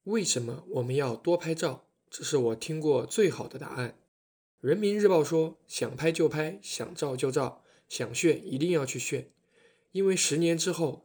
Autentyczny Life Vlog Voiceover AI
Przekształć swoje codzienne chwile w filmowe historie dzięki ciepłemu, przyjaznemu głosowi AI zaprojektowanemu do vlogowania lifestylowego i osobistych narracji.
Naturalny ton
Głębia emocjonalna
Przyjaźnie brzmiąca codzienna narracja